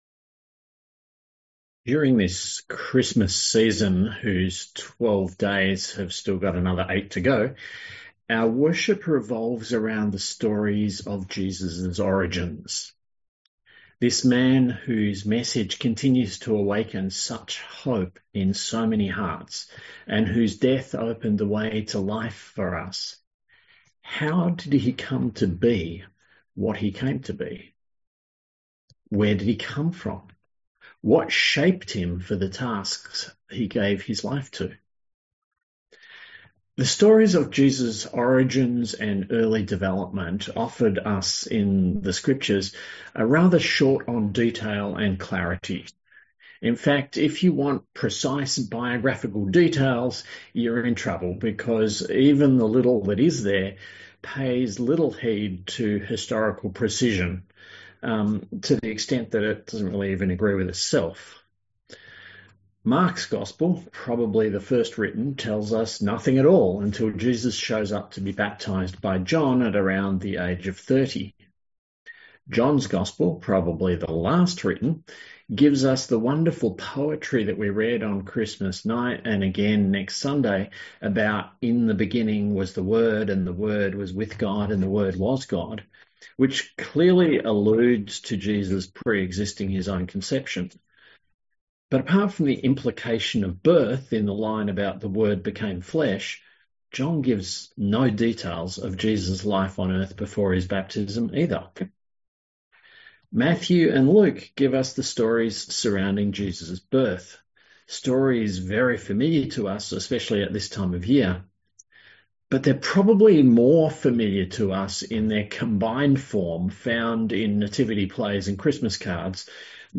A sermon on Luke 2:41-52